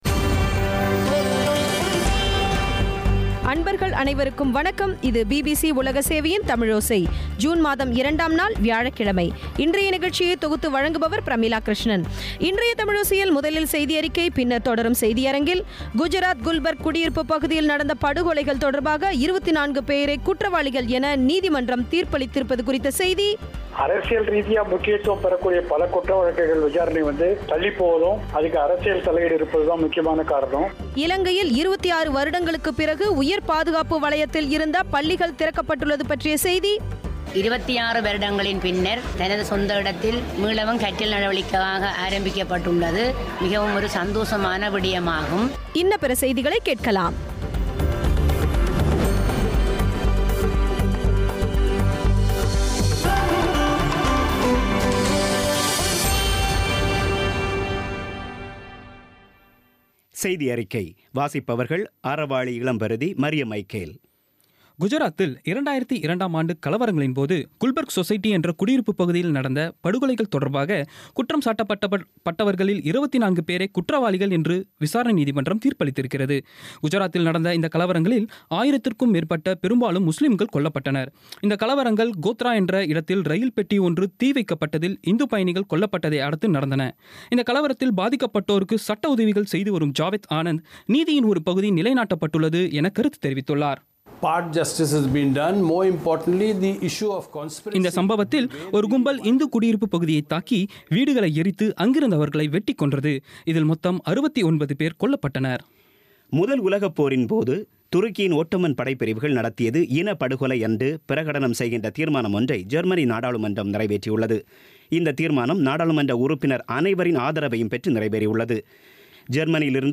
இன்றைய தமிழோசையில், முதலில் செய்தியறிக்கை பின்னர் தொடரும் செய்தியரங்கில், குஜராத் குல்பர்க் குடியிருப்புப் பகுதியில் நடந்த படுகொலைகள் தொடர்பாக 24 பேரை குற்றவாளிகள் என்று நீதிமன்றம் தீர்ப்பளித்திருப்பது குறித்த செய்தி. இலங்கையில் 26 வருடங்களுக்குப் பிறகு கல்விக்கூடங்கள் திறக்கப்பட்டுள்ளது, உள்ளிட்ட பல செய்திகளை கேட்கலாம்.